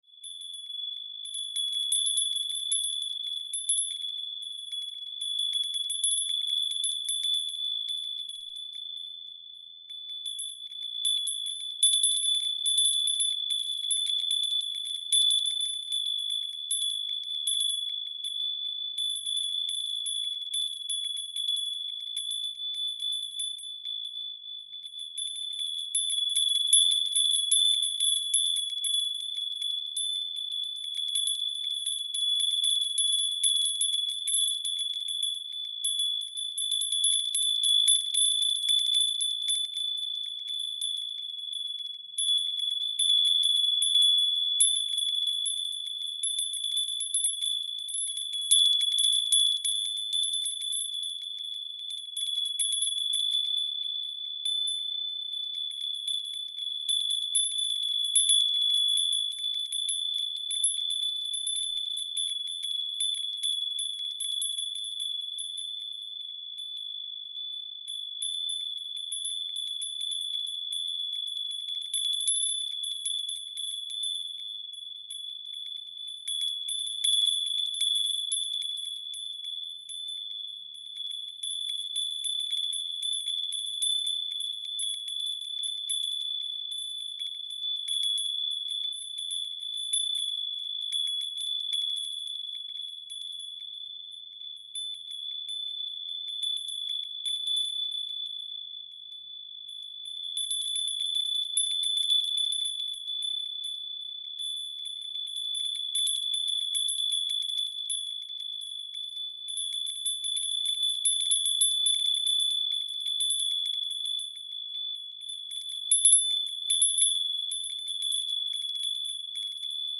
Wind Chimes Sound Effect: Delicate Tinkling Sounds for Relaxation
Soft and delicate tinkling sounds from metallic chimes sway in the wind. Noise of metallic bells in the breeze. Relaxing sounds.
Wind-chimes-sound-effect.mp3